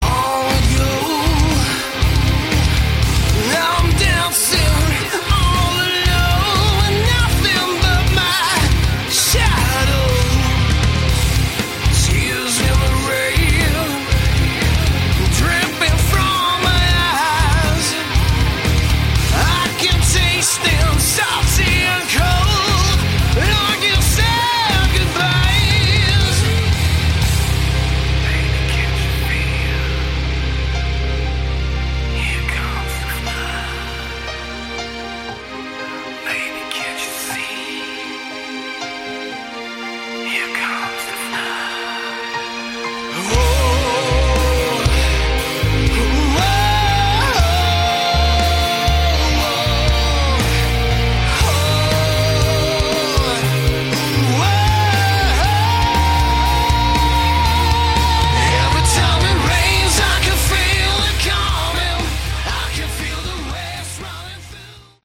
Category: Melodic Metal
guitar, keyboards
vocals
drums